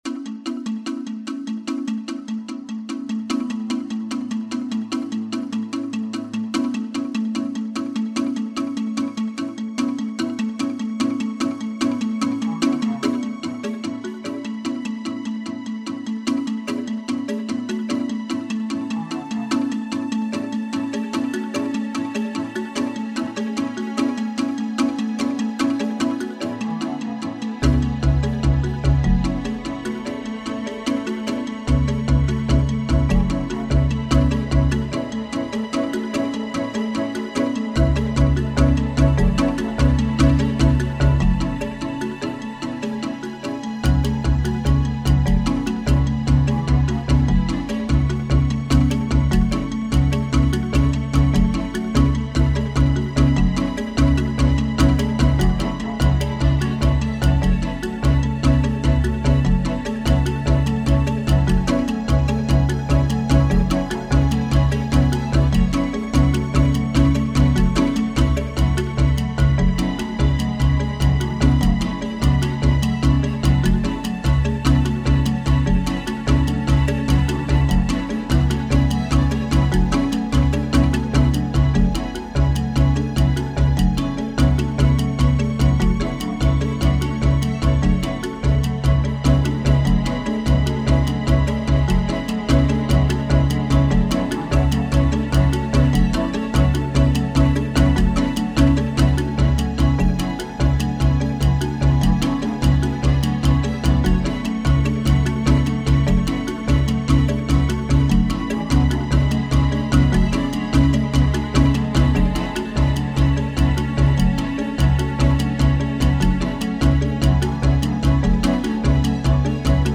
Soundtrack with an African Feel!
Tribal drums, moving panoramic
themes and atmospheres with beautiful African voices